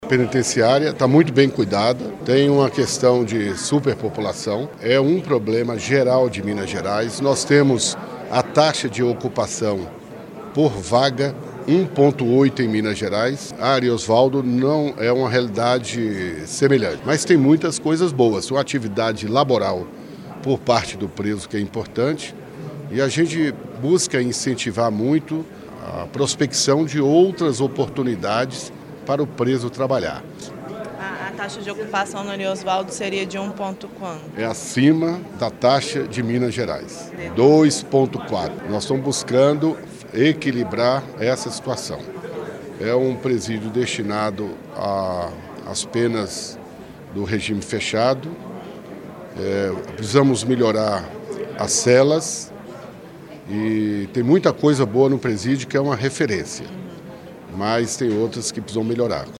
O anúncio de ampliação de vagas em presídios foi feito nesta quarta-feira, 6, durante coletiva de imprensa, com o secretário de Justiça e Segurança Pública, general Mario Araujo.
secretário de Justiça e Segurança Pública, general Mario Araujo